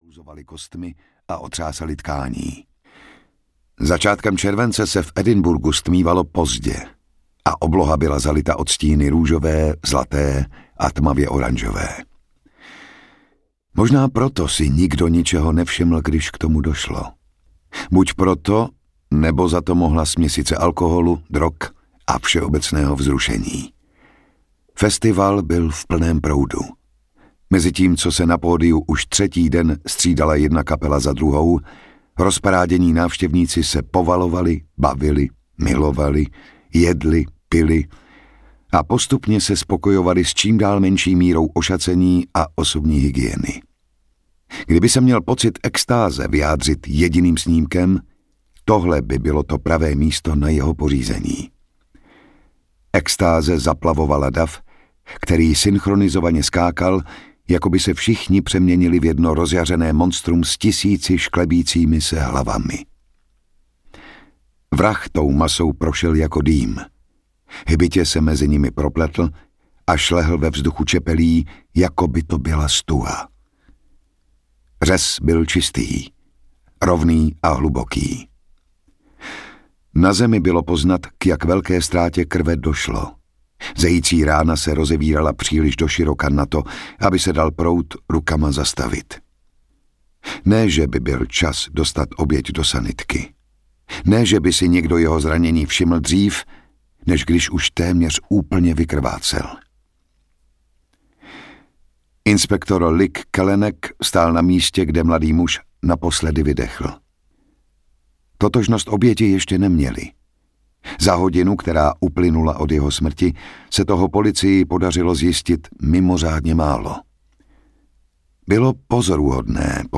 Dokonalá kořist audiokniha
Ukázka z knihy
• InterpretJan Šťastný